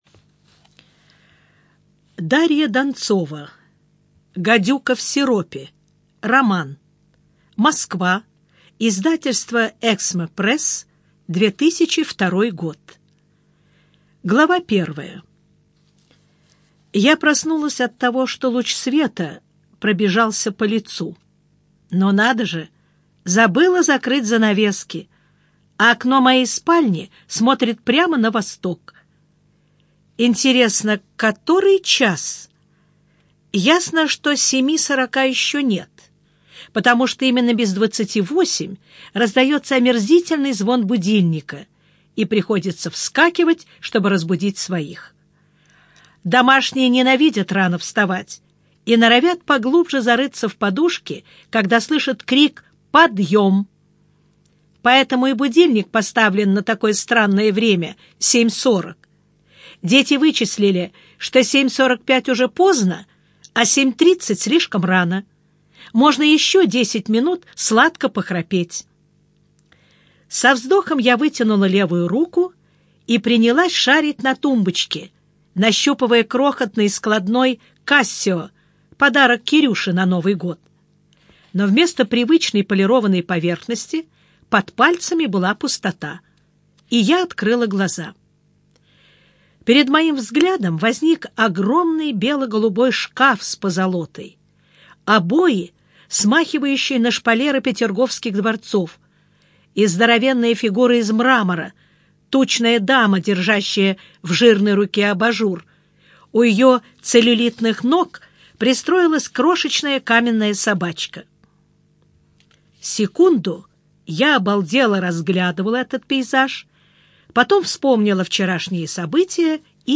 Аудиокнига Гадюка в сиропе - купить, скачать и слушать онлайн | КнигоПоиск